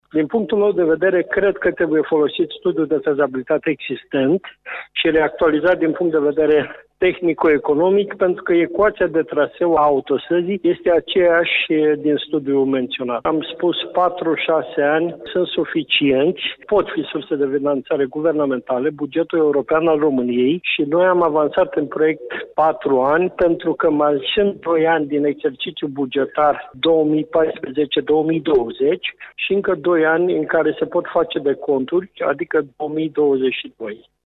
Deputatul Petru Movilă a vorbit şi despre varianta de lucru care se bazează pe actualizarea studiului de fezabilitate existent pentru finalizarea investiţiei într-un timp de maximum 6 ani: